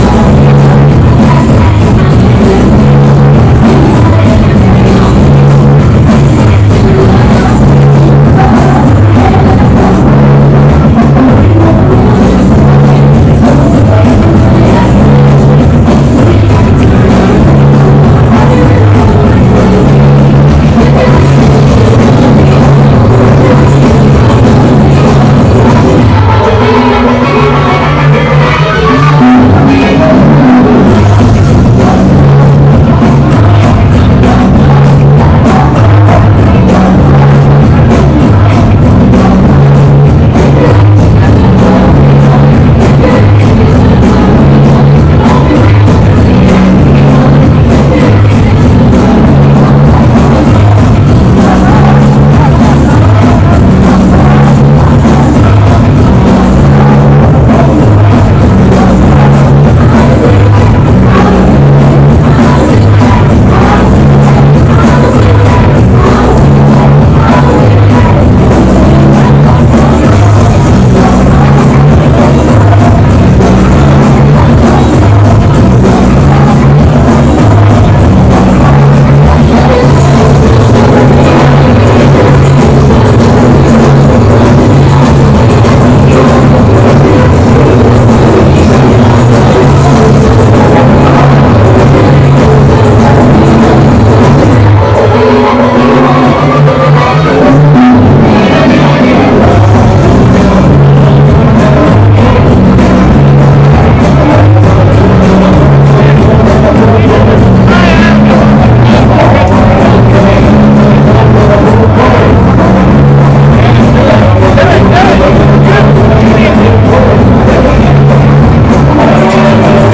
See previous Boo/Tweet for sound-level apologies.